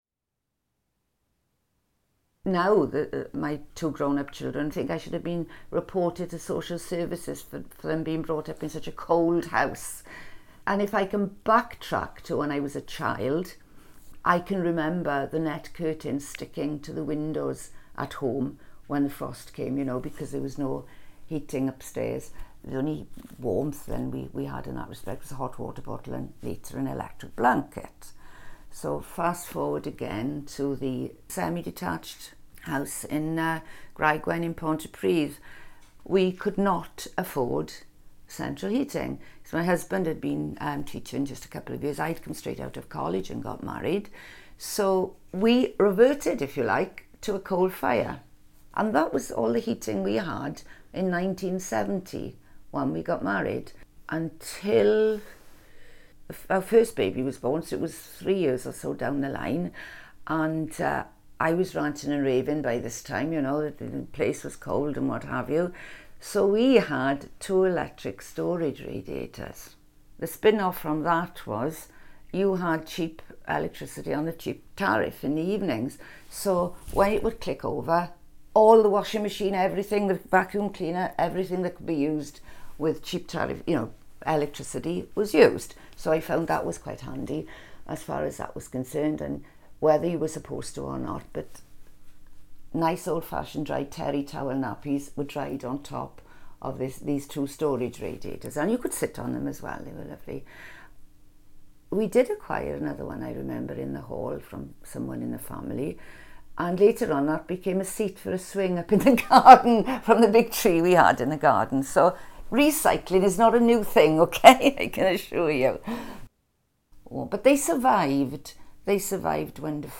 Edited oral history interview Ynysybwl 2015